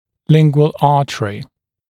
[‘lɪŋgwəl ‘ɑːtərɪ][‘лингуэл ‘а:тэри]язычная артерия